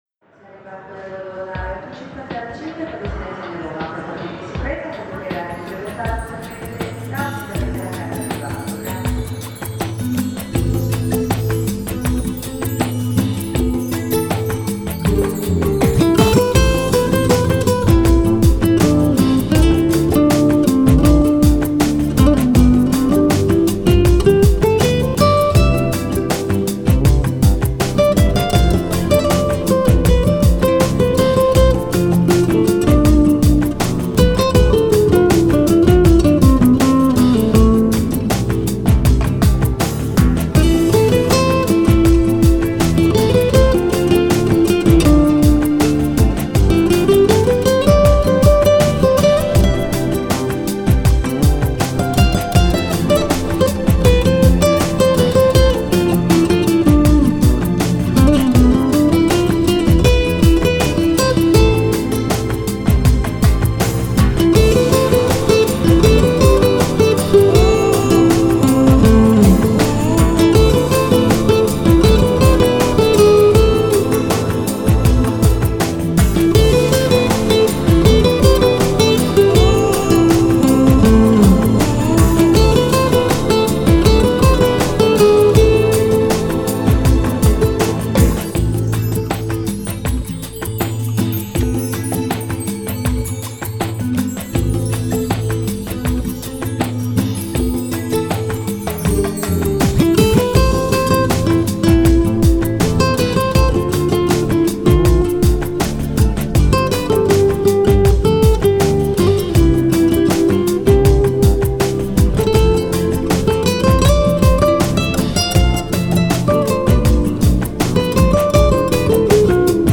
Жанр: Lounge, Chillout